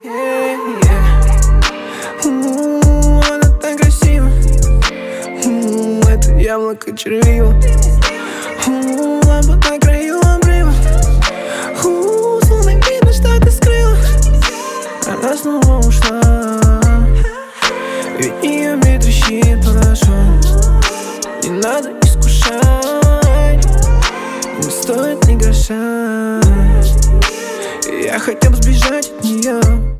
лирика
Хип-хоп
спокойные
Bass
Спокойный рингтон, отлично подойдёт для раздумья.